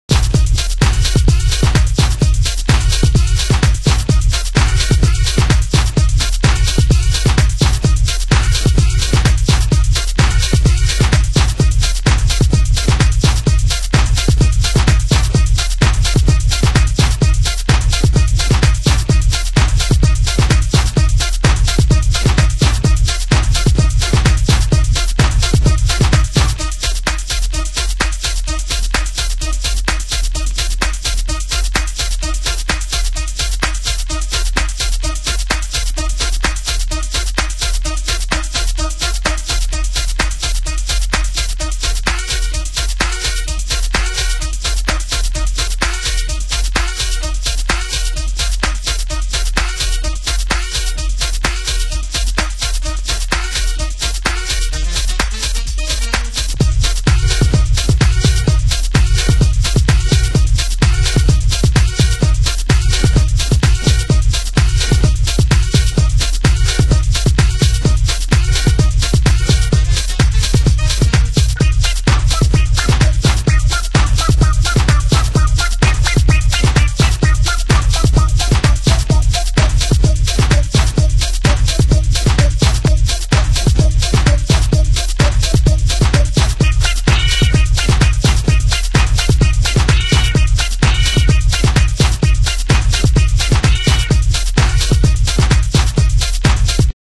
盤質：少しチリパチノイズ有